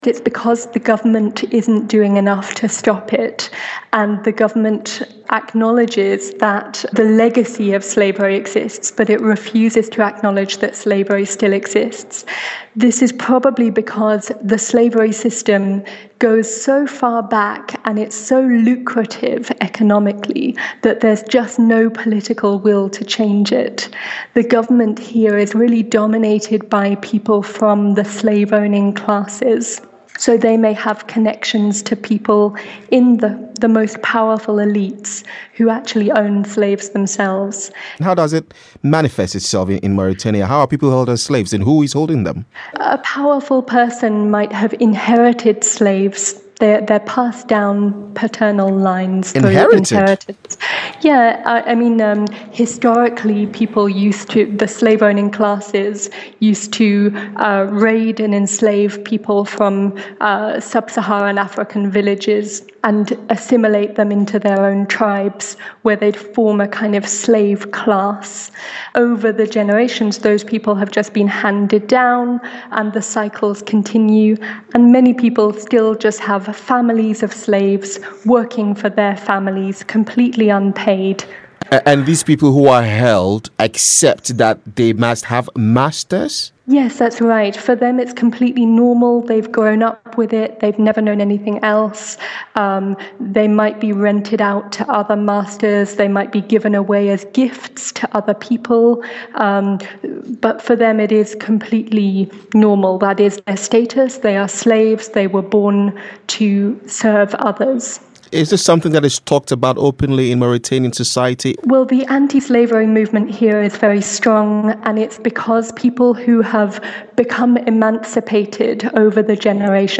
Why slavery still persists in Mauritania - An Interview with Anti-Slavery International